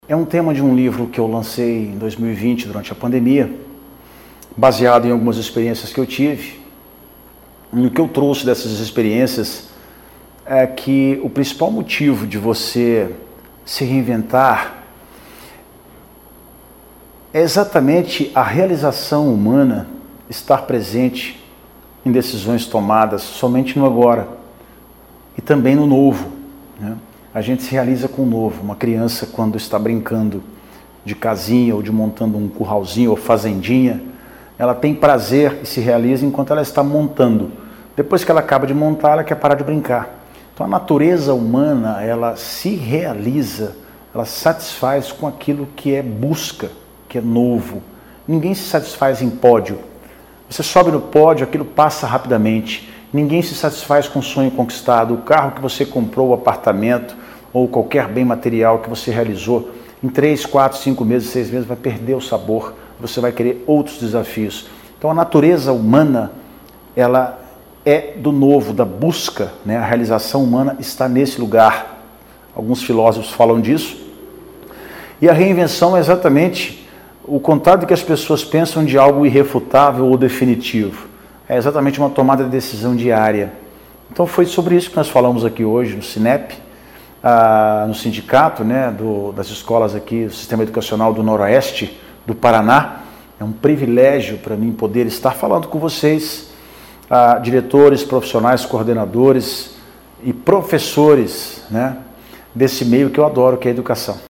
A palestra de abertura foi do cantor, compositor e escritor Léo Chaves. Ele falou sobre o tema de um livro que lançou na pandemia e que trata sobre como o ser humano pode se reinventar.